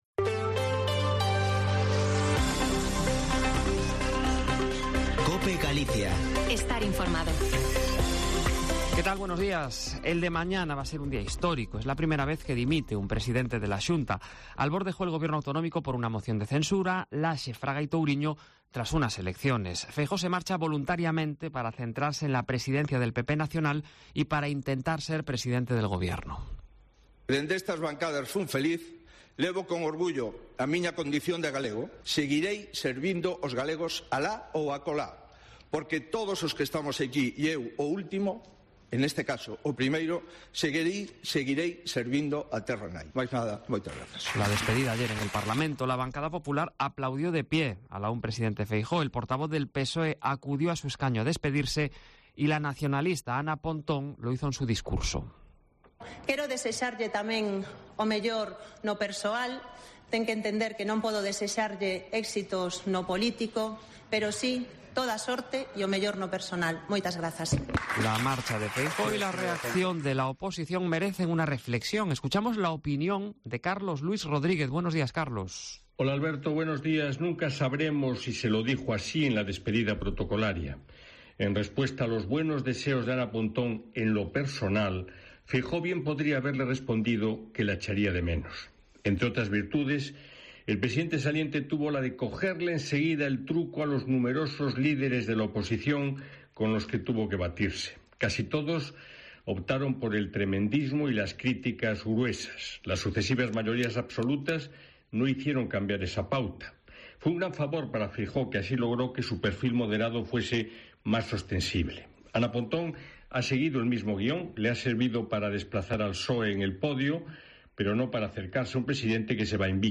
Hemos salido a la calle a preguntar y la mayoría saben quién es el vicepresidente de la Xunta y el papel que va a jugar desde el próximo mes de mayo.